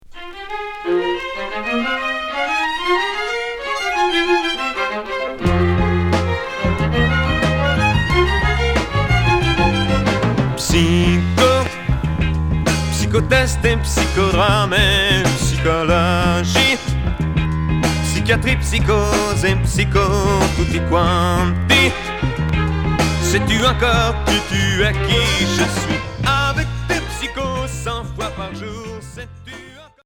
Chanteur 60's Orchestre